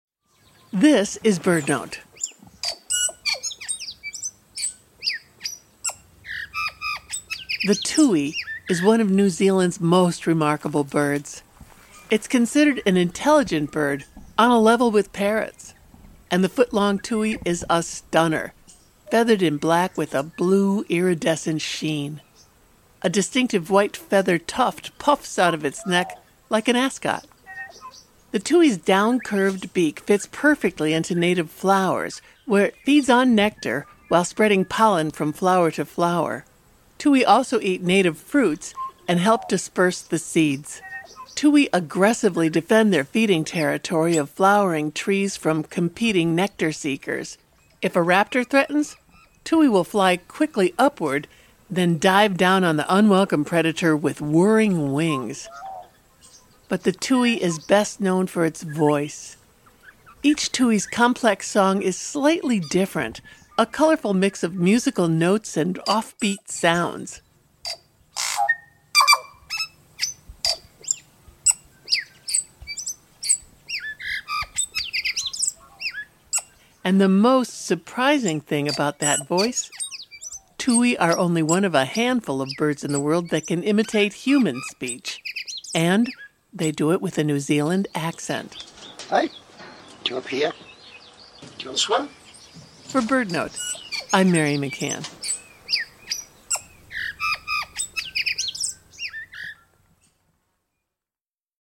Each Tui’s complex song is slightly different, a colorful mix of musical notes and offbeat sounds. It’s one of the few birds that can imitate human speech — and even accents.